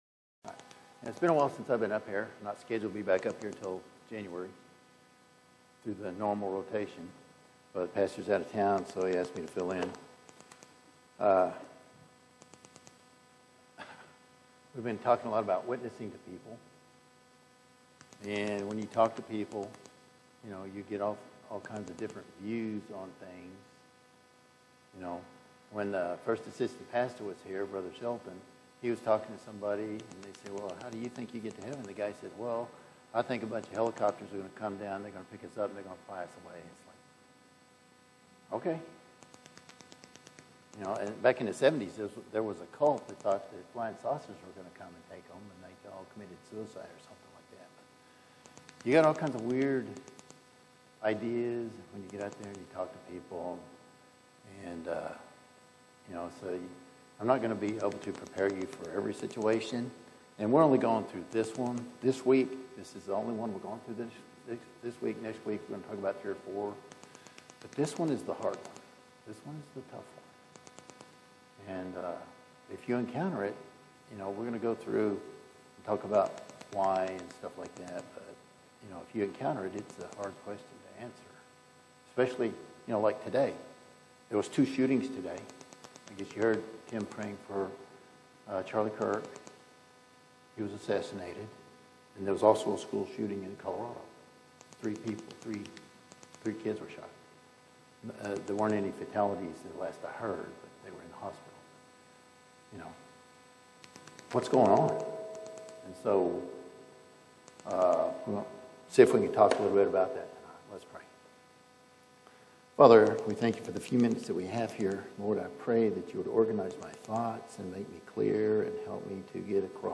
Wednesday PM Service